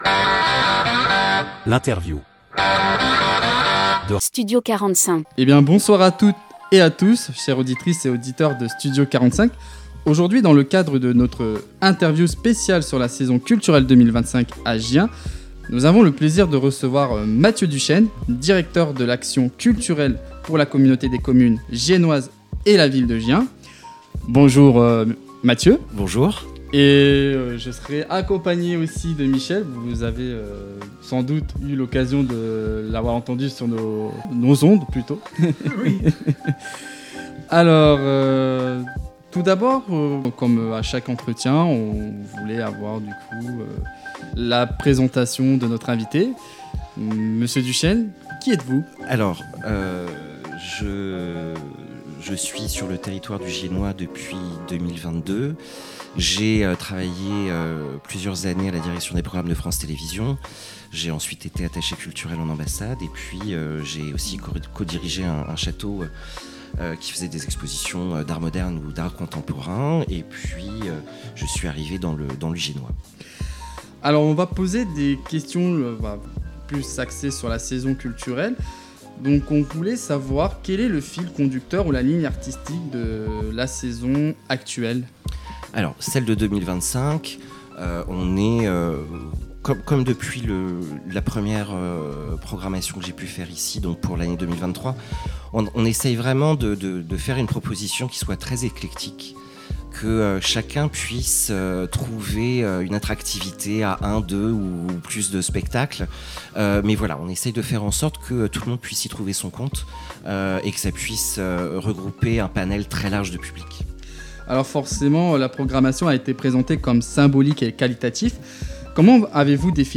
L'Interview de Studio 45